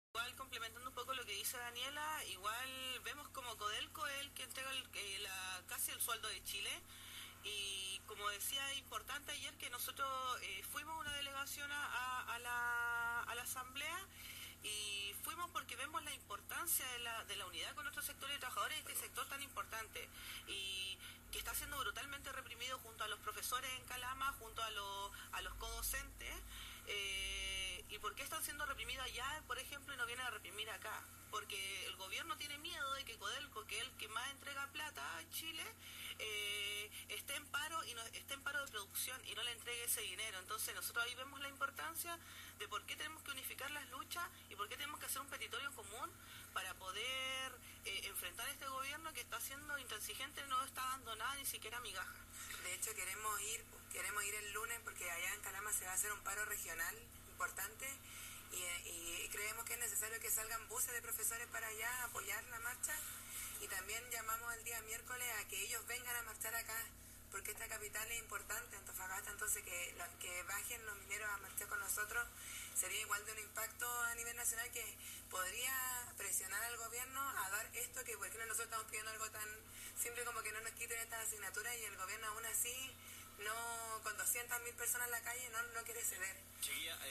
Entrevista radial a profesoras de la Agrupación Nuestra Clase a raíz del Paro docente